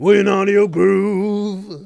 GROOVE1B.WAV